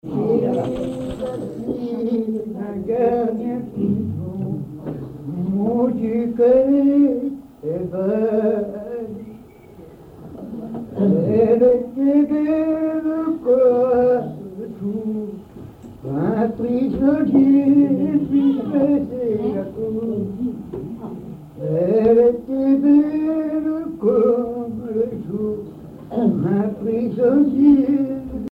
Localisation Cancale (Plus d'informations sur Wikipedia)
Genre strophique
Catégorie Pièce musicale inédite